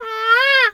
bird_peacock_squawk_05.wav